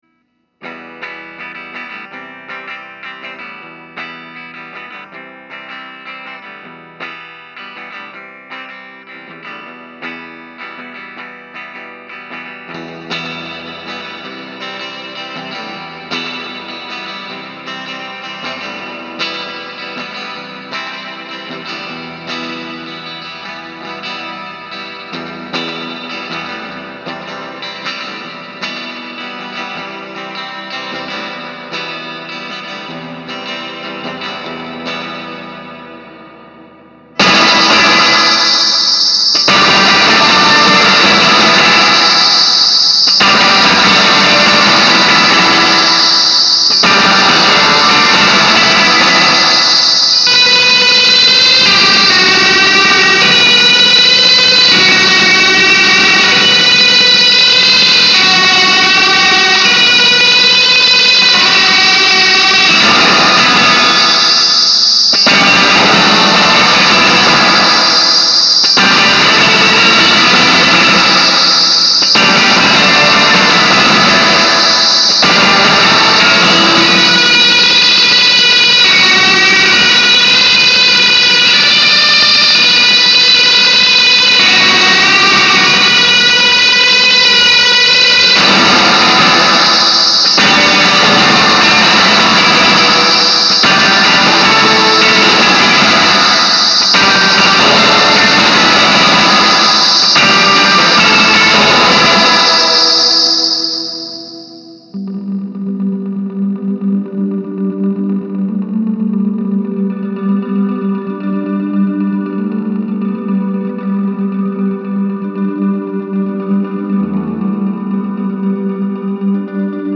clip :drool: sounds pretty awesome at the beginning and the end... the max-feedback part in the middle kinda annoys me, but i think that's the point
sunshinereverb.mp3